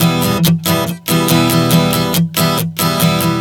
Strum 140 Em 04.wav